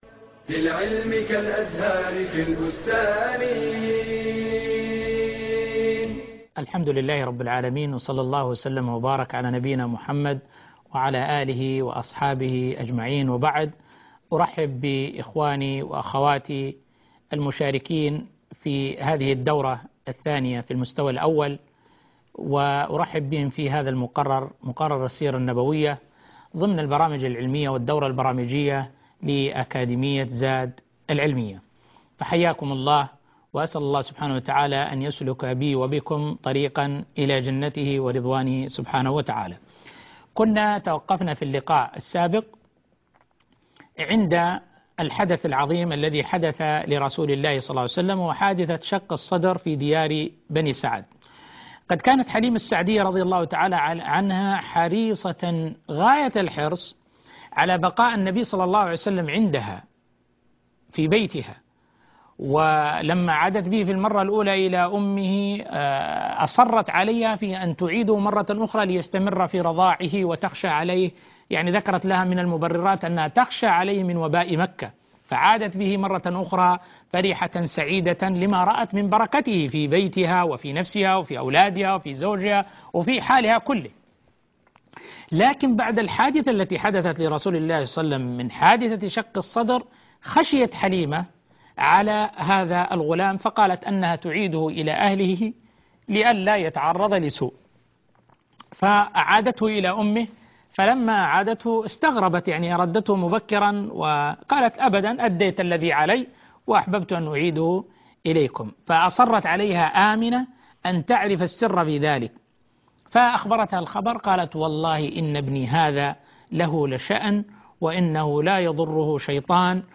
المحاضرة الرابعة - حادثة شق الصدر وتربية جده له صلى الله عليه وسلم